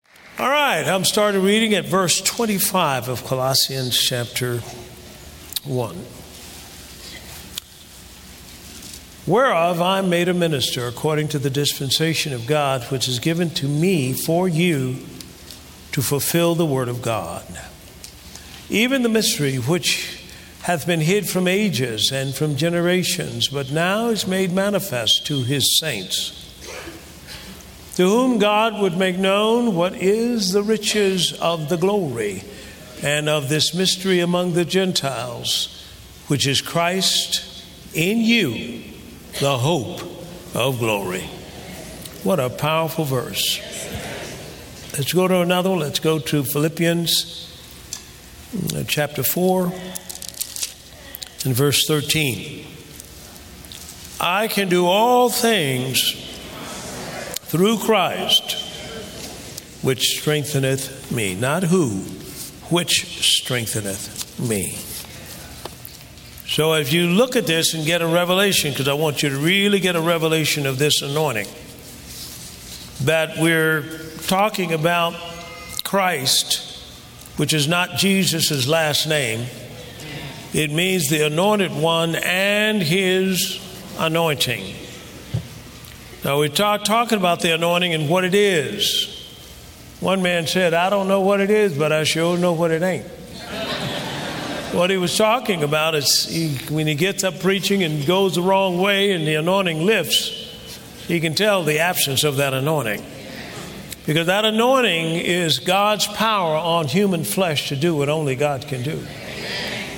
(2 Teachings) As a believer the power of God resides in you and will bring you into a high-performance level that only God can enable.